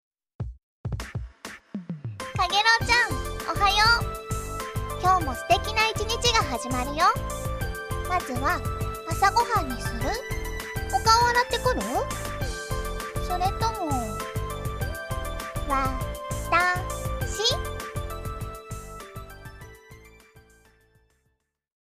目覚ましメッセージはちょっと恥ずかしかったけど……
調子に乗って、背後さんがやってみた、らしいよ。